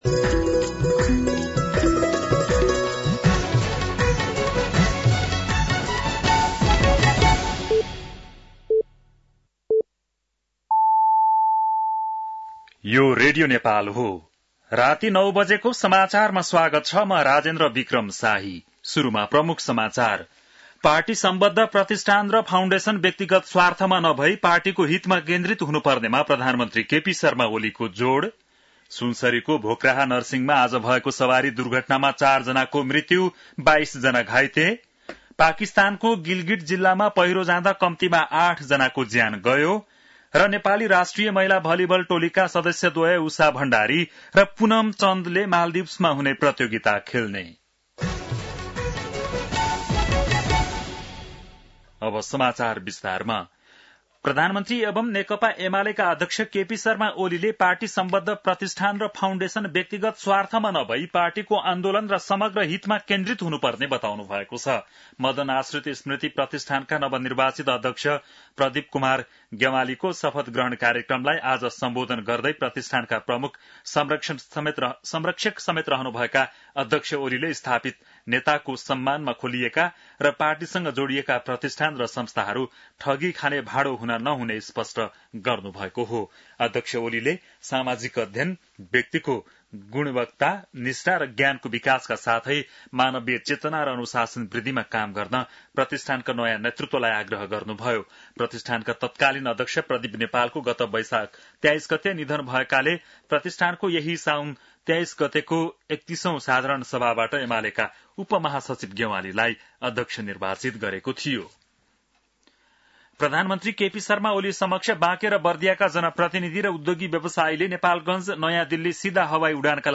बेलुकी ९ बजेको नेपाली समाचार : २६ साउन , २०८२
9-PM-Nepali-NEWS-4-26.mp3